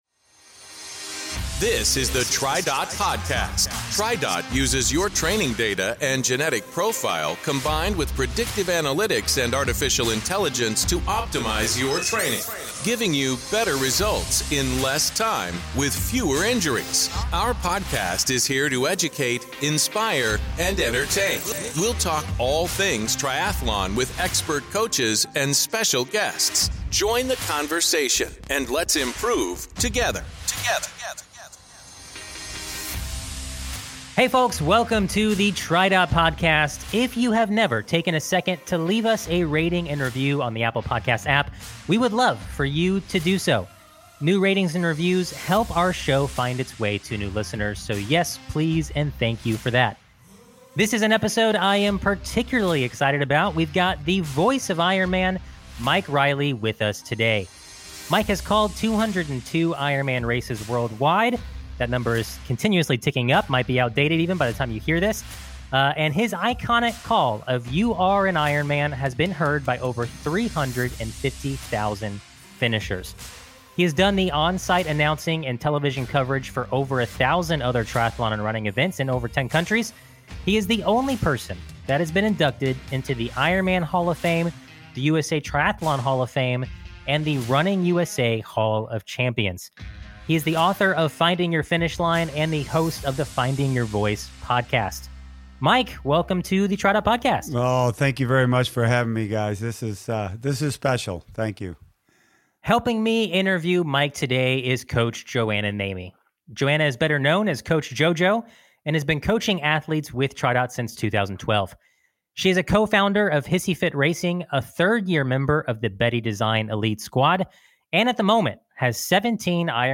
On the heels of the announcement that he'll soon be calling his last race, Mike Reilly, “The Voice of IRONMAN,” joins us for today's episode!
Tune in for a storytelling episode as Mike recalls highlights from his career and special athlete stories that are sure to entertain and inspire.